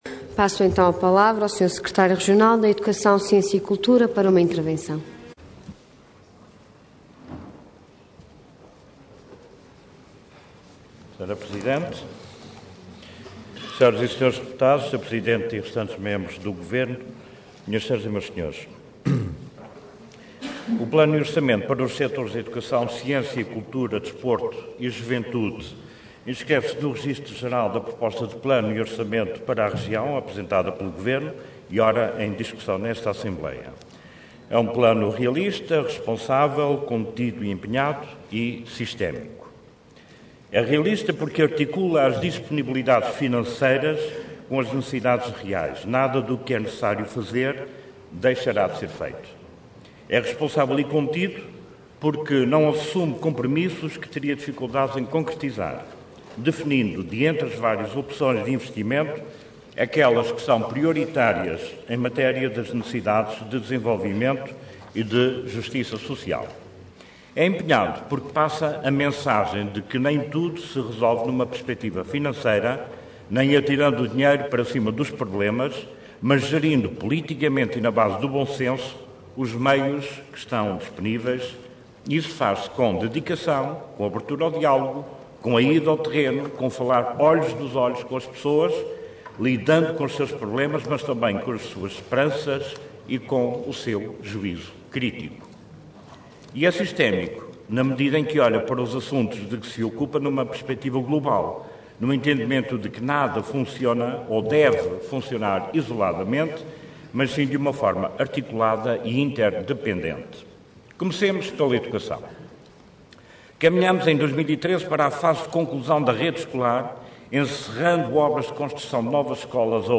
Detalhe de vídeo 20 de março de 2013 Download áudio Download vídeo Diário da Sessão X Legislatura Plano e Orçamento para 2013 Intervenção Intervenção de Tribuna Orador Luiz Fagundes Duarte Cargo Secretário Regional da Educação, Ciência e Cultura Entidade Governo